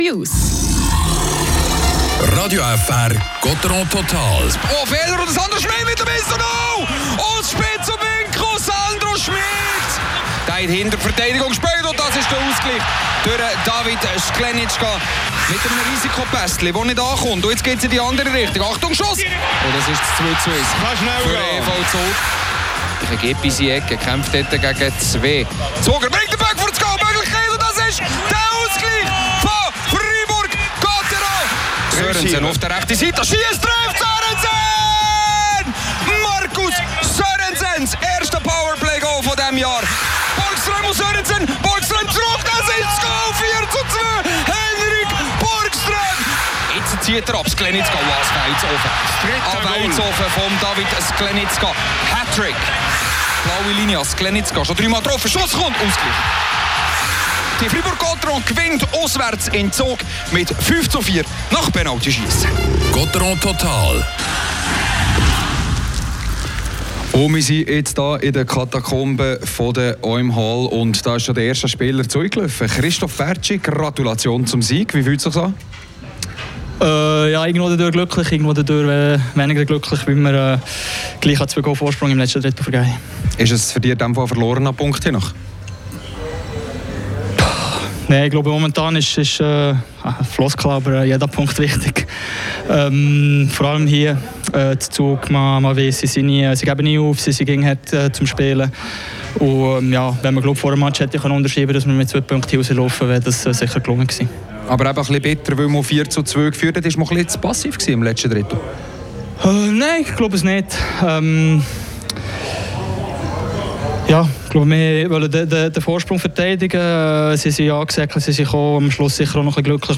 Hier gibt’s die Highlights und das Interview
Spielanalyse